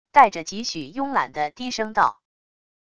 带着几许慵懒的低声道wav音频